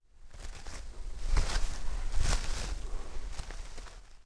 脚步走在丛林zth070524.wav
通用动作/01人物/01移动状态/06落叶地面/脚步走在丛林zth070524.wav
• 声道 單聲道 (1ch)